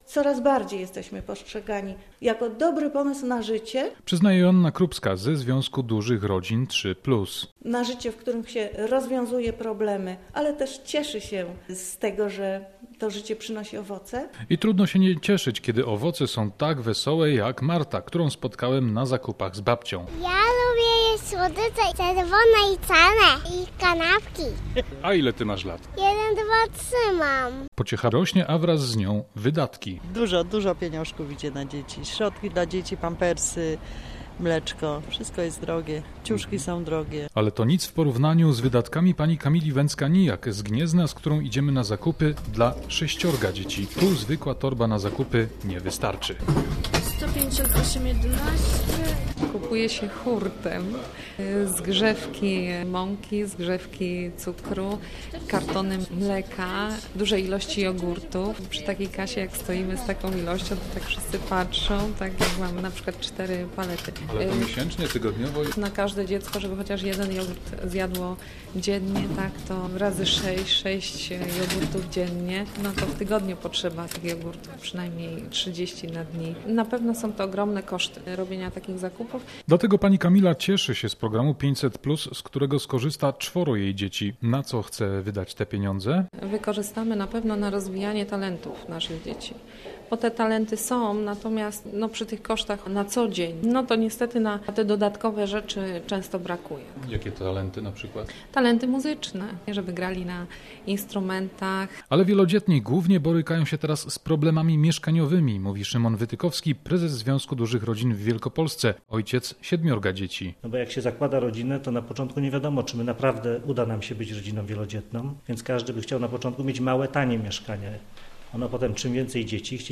Oczywiście zupełnie inaczej widzą to sami zainteresowani, z którymi na codziennych zakupach był nasz reporter.